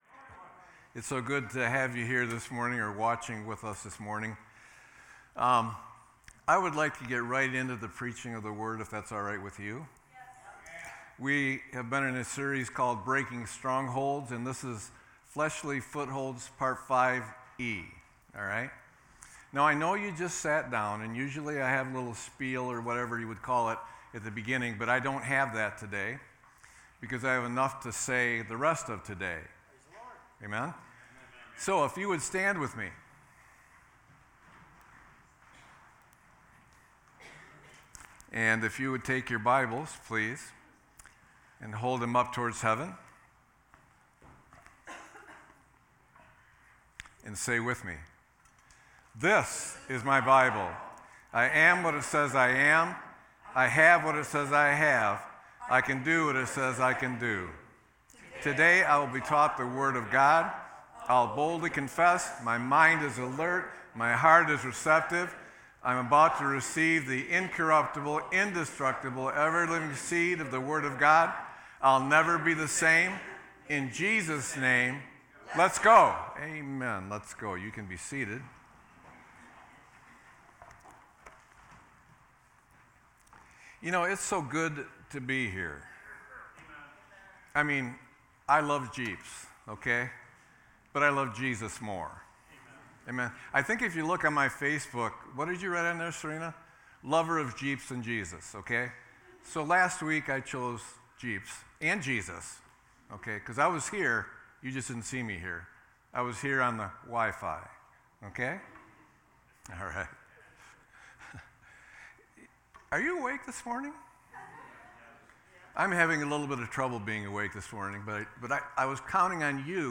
Sermon-6-09-24.mp3